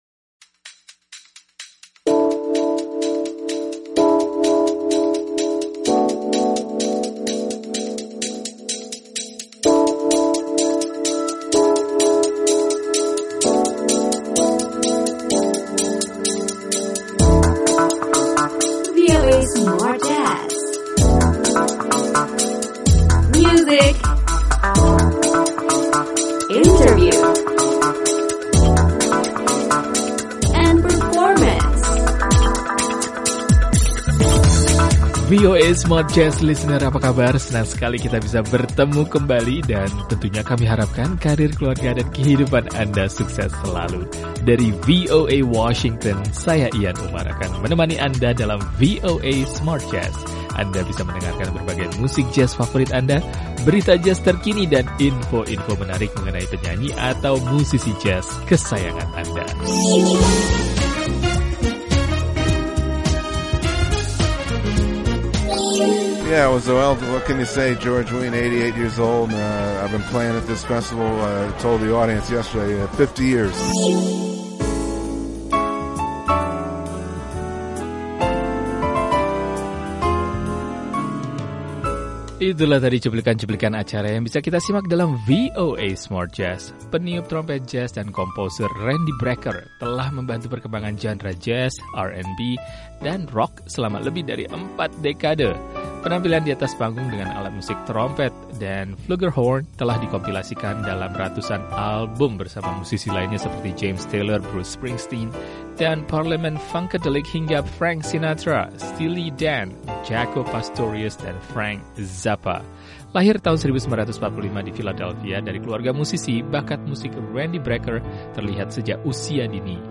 trompet jazz